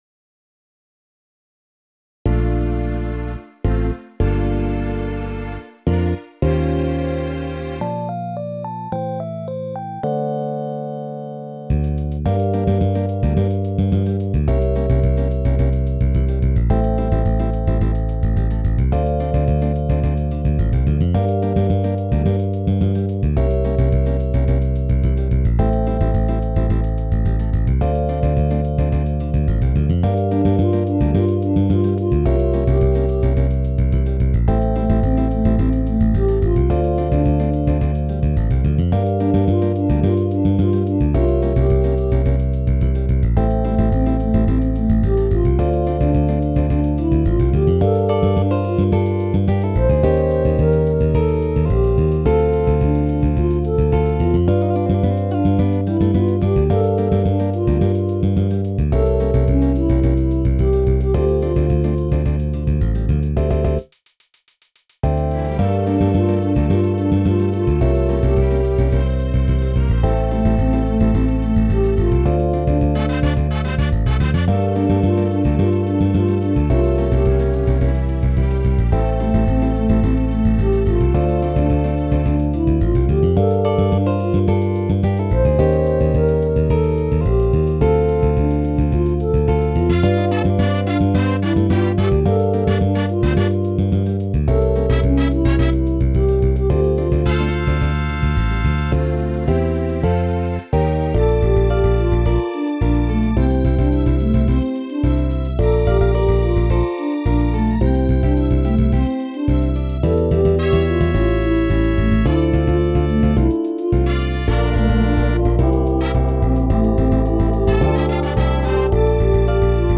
メンバーが揃わない社会人バンドならではのフレキシブルアレンジ。
最低4人(Vo.&タンバリン,B,G&バックVo.,Kb&バックVo.)で演奏可能。